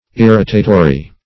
Search Result for " irritatory" : The Collaborative International Dictionary of English v.0.48: Irritatory \Ir"ri*ta*to*ry\, a. Exciting; producing irritation; irritating.